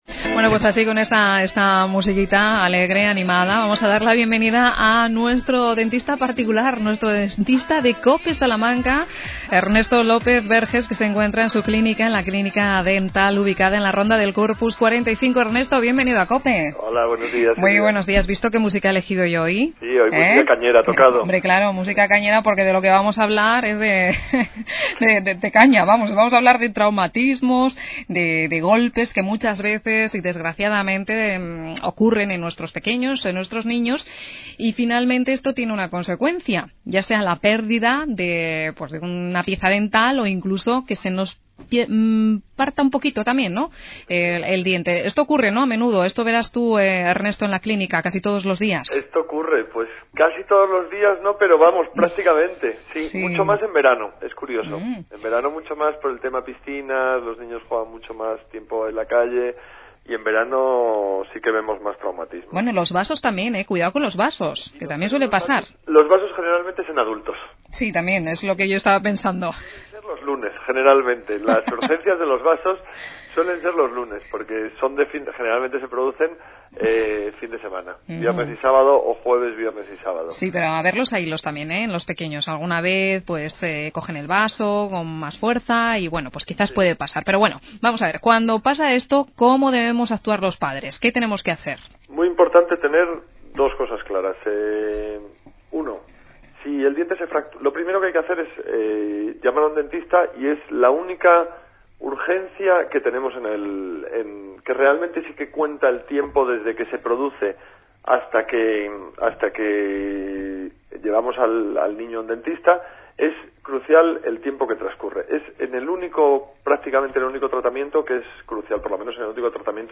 Todo ello, en el Cadena Cope Salamanca,